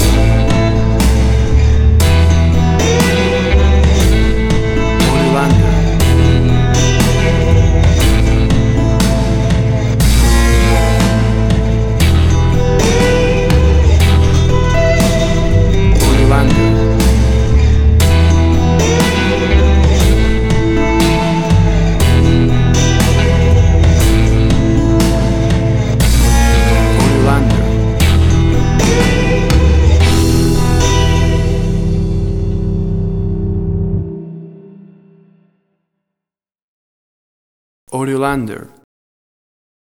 Old American Blues with a classic Vintage and raw vibe.
Tempo (BPM): 60